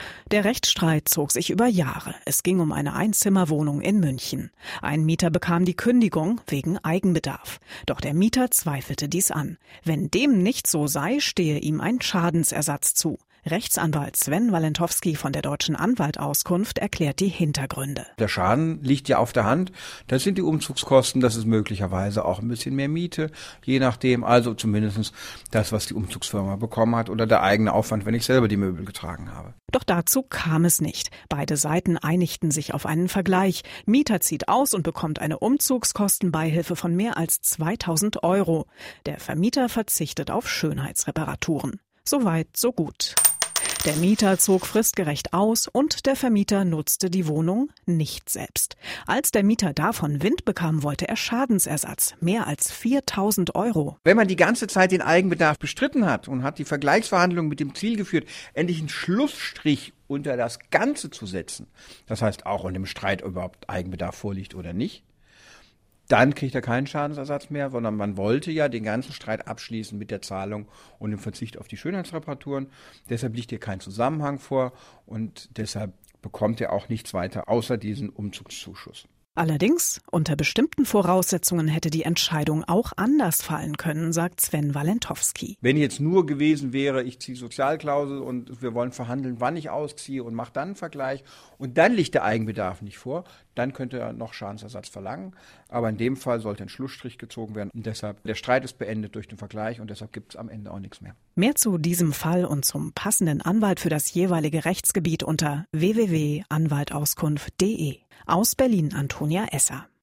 DAV, O-Töne / Radiobeiträge, Ratgeber, Recht, , , , , ,
Magazin: Schadensersatz bei vorgetäuschtem Eigenbedarf